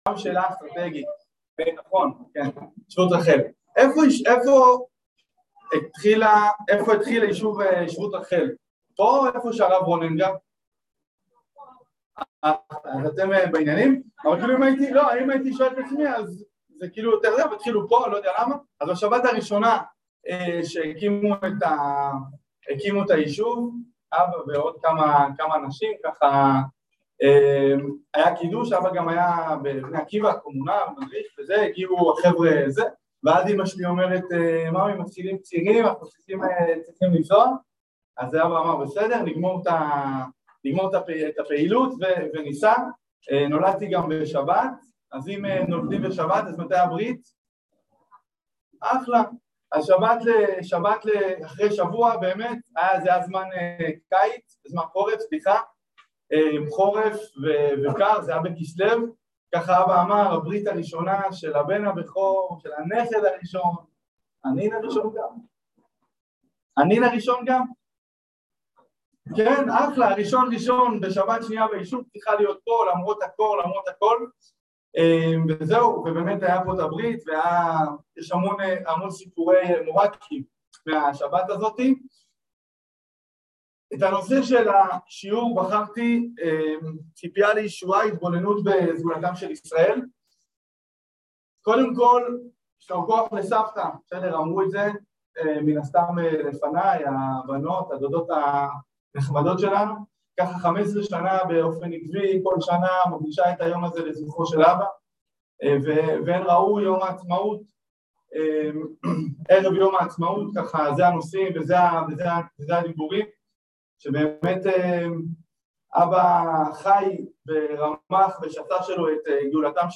בציפיה לגאולה שלמה | יום עיון לקראת יום העצמאות תשפ"ב | מדרשת בינת